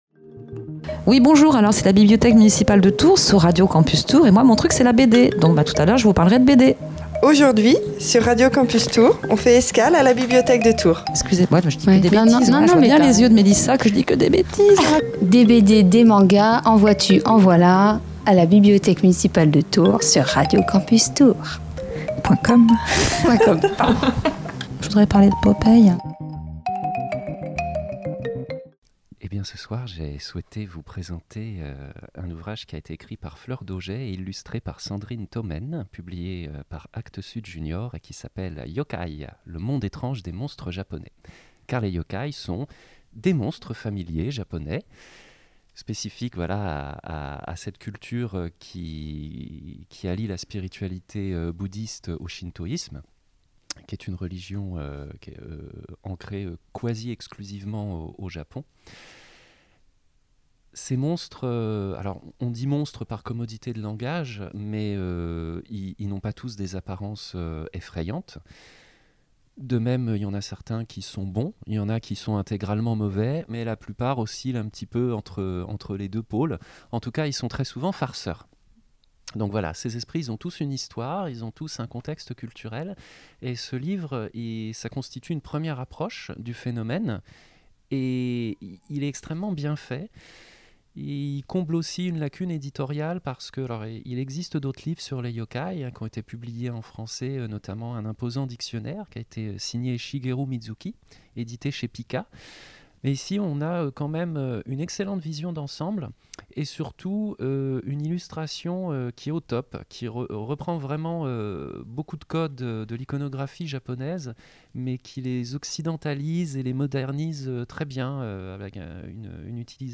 Nouvelle fournée de chroniques par les médiathécaires de la Bibliothèque Municipale de Tours ! Ces chroniques sont diffusées dans la quotidienne La Méridienne.